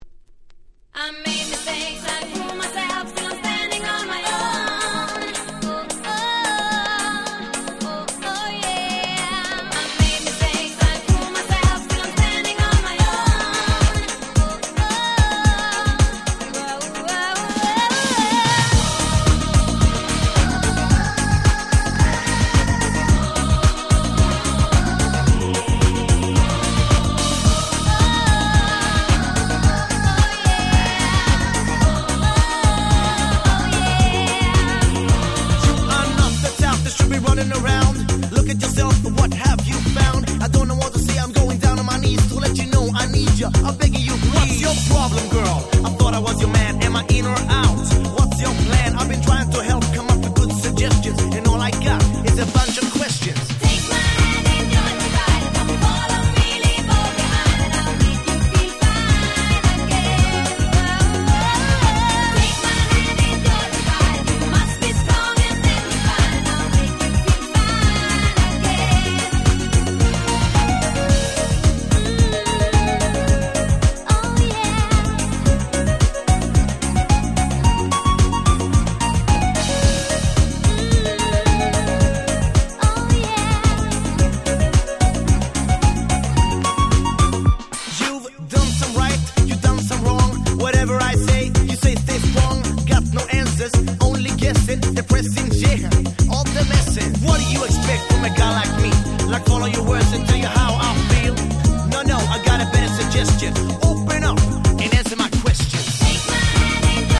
この辺のRagga Pop物、キャッチーでやっぱり最高ですよね。